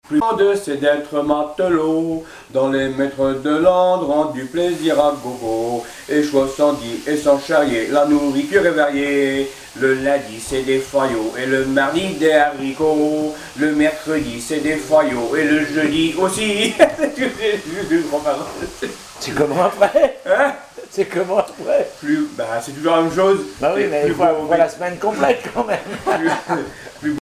Genre strophique
Témoignage et chansons maritimes
Pièce musicale inédite